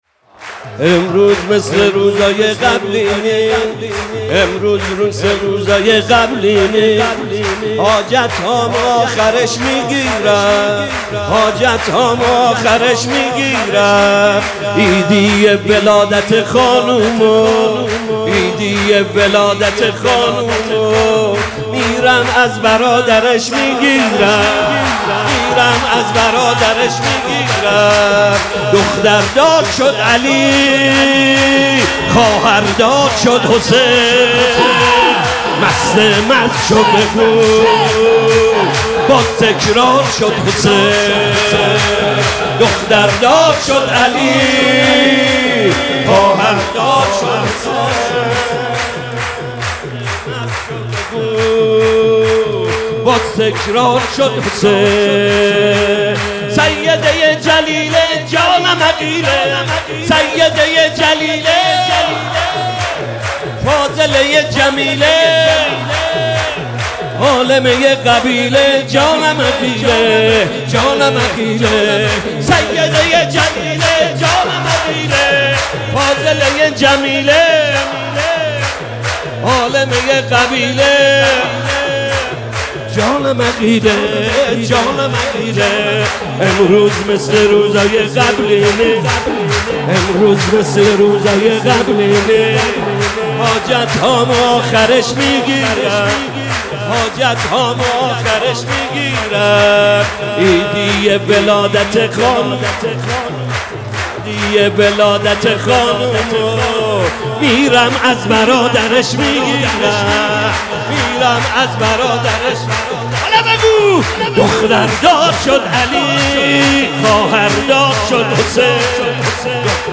صوت/ مولودی خوانی حاج محمدرضا طاهری